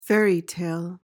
PRONUNCIATION:
(FER-ee-tayl)